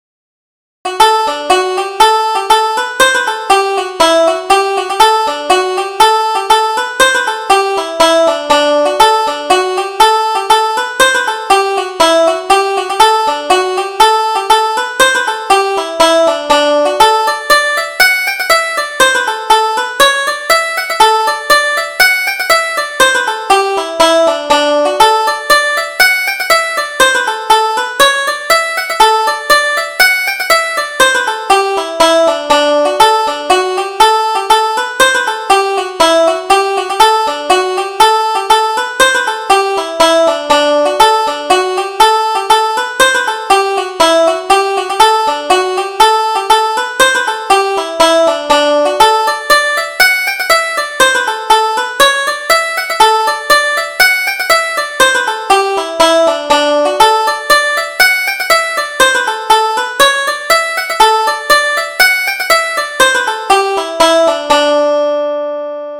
O'Sullivan's Fancy: Polka
Irish Traditional Polkas